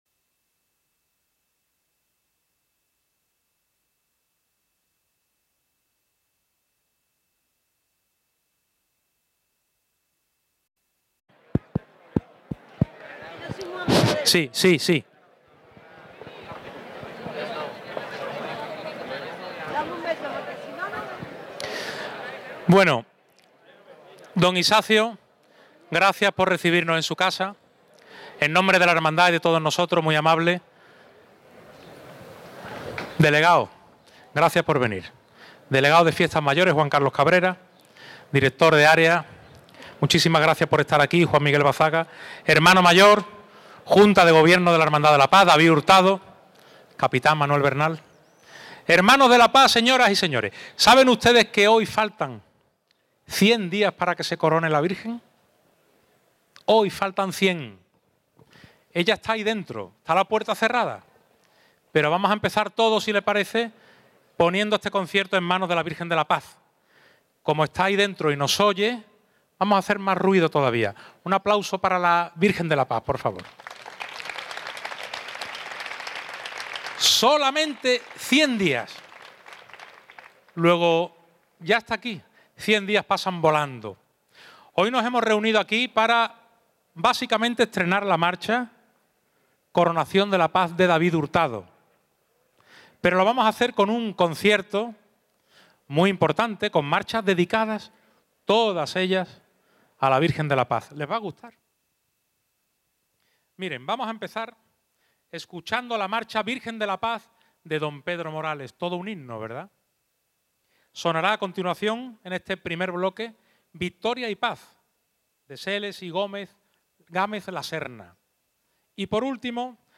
Audio del concierto (mp3)
El pasado 23 de junio celebramos en los jardines de la Parroquia de San Sebastián el concierto de presentación oficial de la marcha «Coronación de la Paz», obra de D. David Hurtado, compuesta expresamente para la hermandad y para celebrar este magno acontecimiento.
Pero, además de Coronación de la Paz, ahora les ofrecemos el sonido de todo el concierto.